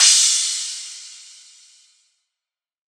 Metro Cymbal [Musty].wav